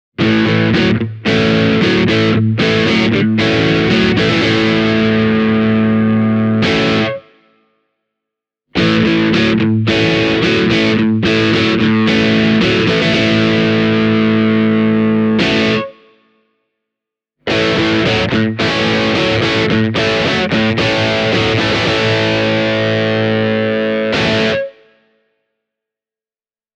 The Seymour Duncan P-90s give you a wide range of different tones on the Liekki, from jazzy warmth all the way to gritty Rock.
flaxwood-liekki-e28093-drive.mp3